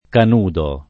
Canudo [ kan 2 do ]